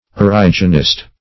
Origenist \Or"i*gen*ist\, n. A follower of Origen of Alexandria.
origenist.mp3